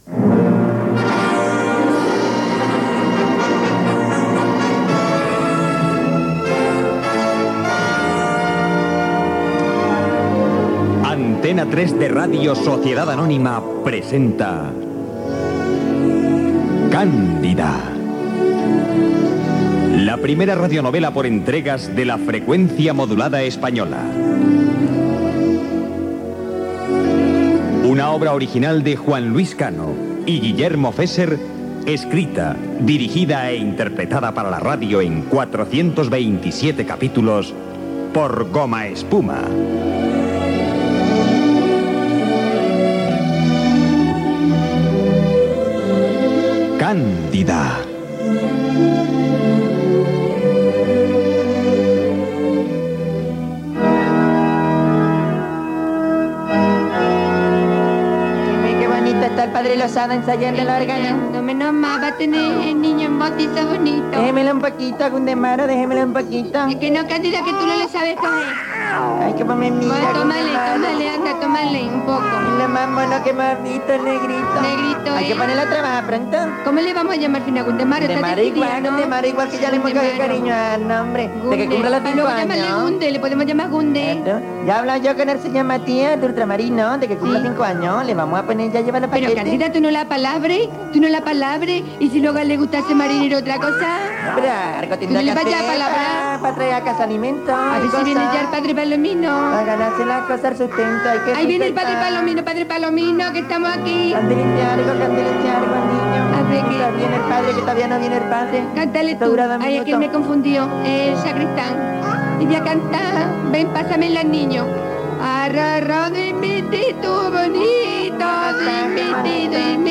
Careta i inici del capítol de la sèrie "Cándida" interpretat per Gomaespuma
Entreteniment
FM